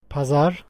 Ääntäminen
Ääntäminen Tuntematon aksentti: IPA: /pɑ.zɑɾ/ Haettu sana löytyi näillä lähdekielillä: turkki Käännös Ääninäyte Substantiivit 1.